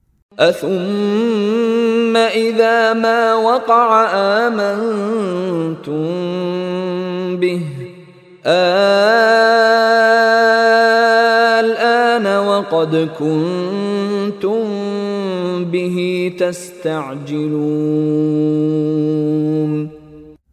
Contoh Bacaan dari Sheikh Mishary Rashid Al-Afasy
Mad lazim Kalimi Mukhaffaf ini dibaca dengan kadar pemanjangan 6 harakat sahaja sama seperti mad-mad lazim yang lain.